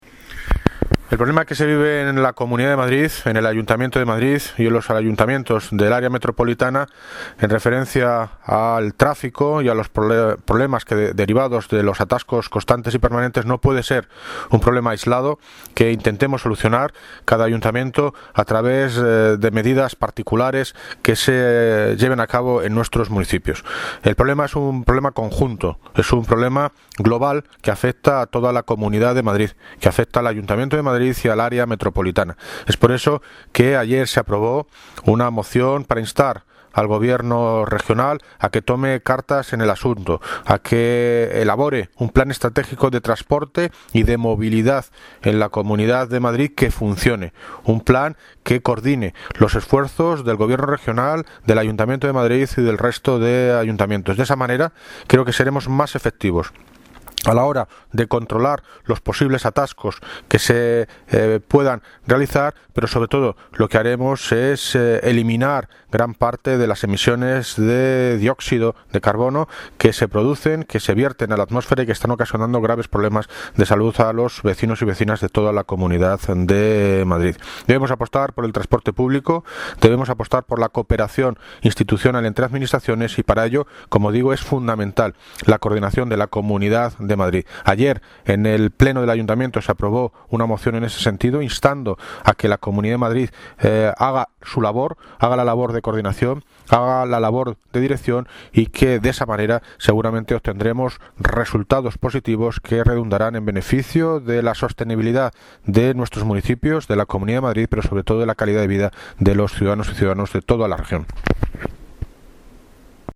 Audio - David Lucas (Alcalde de Móstoles) Sobre Moción Cambio Climático